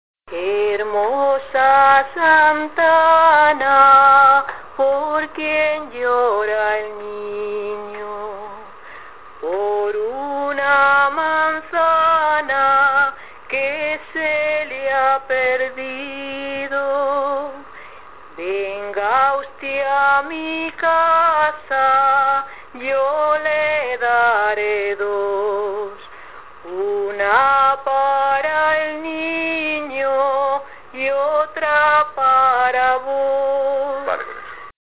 Las canciones de cuna en La aldea.